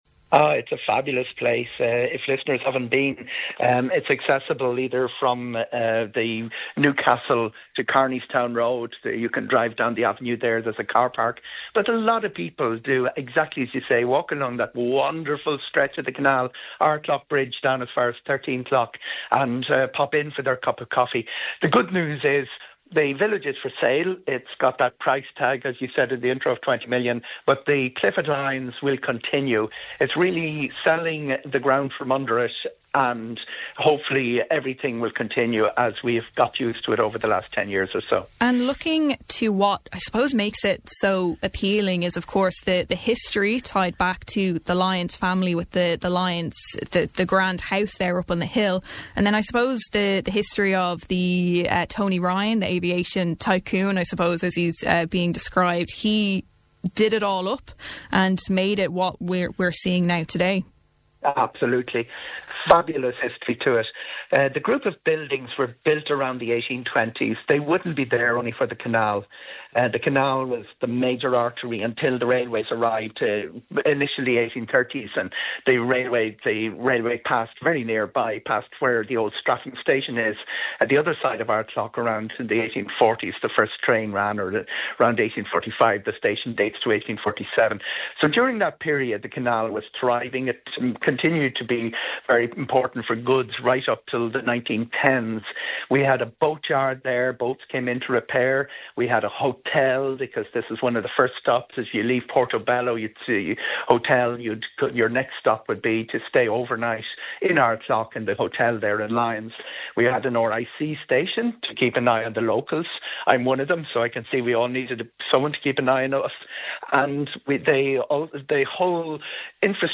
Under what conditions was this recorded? Speaking on this mornings Kildare Today